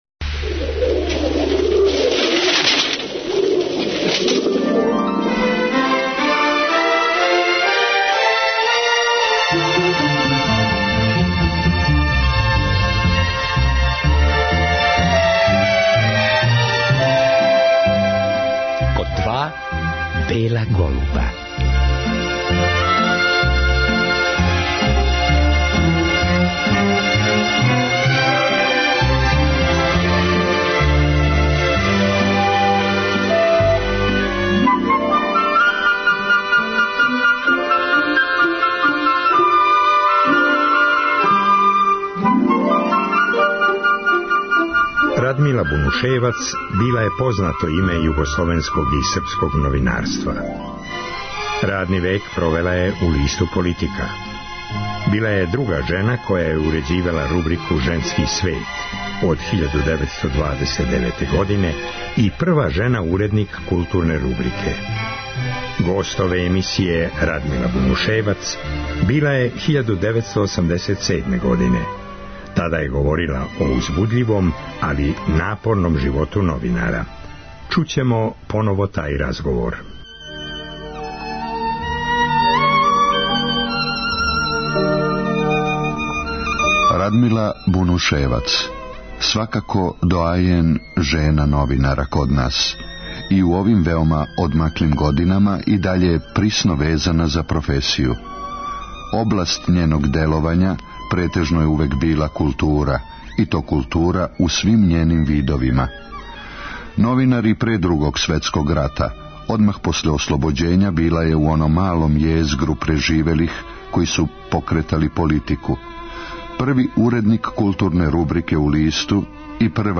Реприза